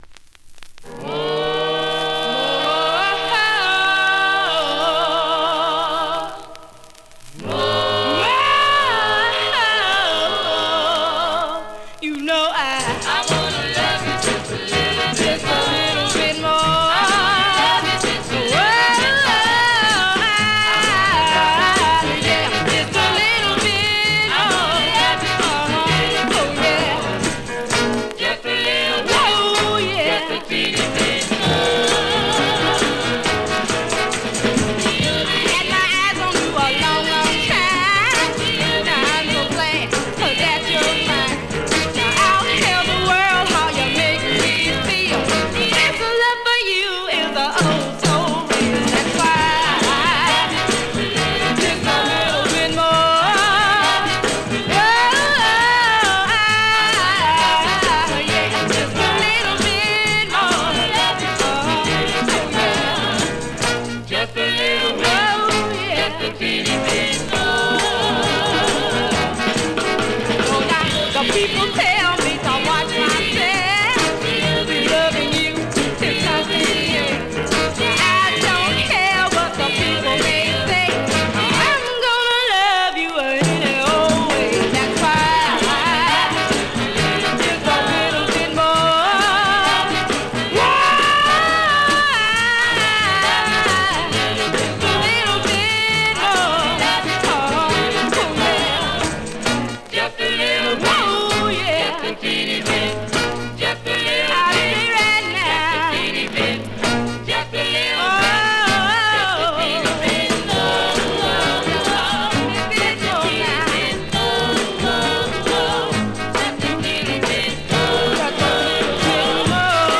Vinyl has a few light marks plays great .
Great up-tempo Rnb / Mod dancer .